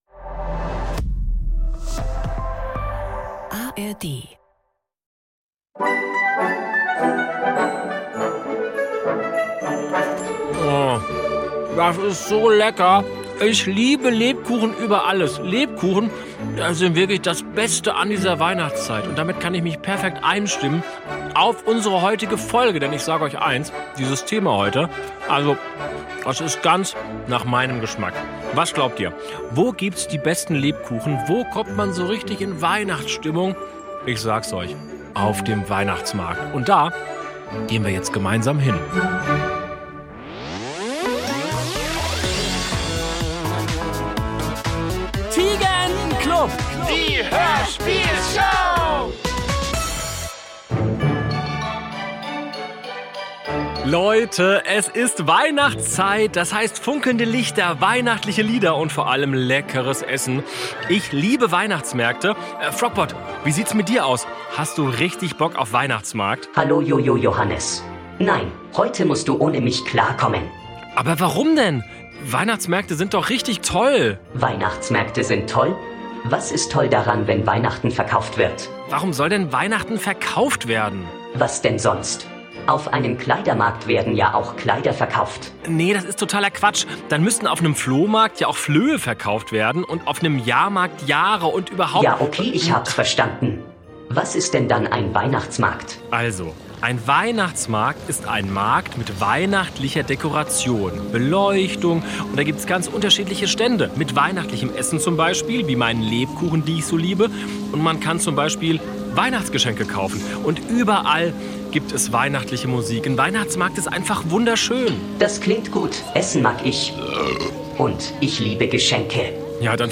Und was wird da so schräg auf der Blockflöte gespielt?